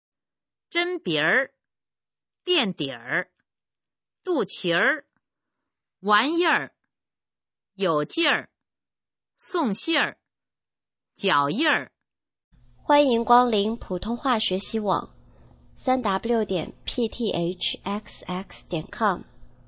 首页 视听 学说普通话 儿化词语表
普通话水平测试用儿化词语表示范读音第13部分